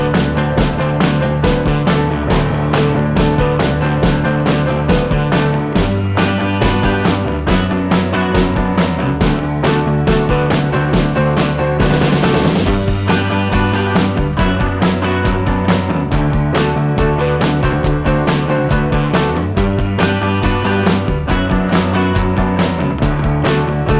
No Backing Vocals Rock 3:36 Buy £1.50